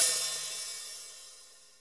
HAT POP HH09.wav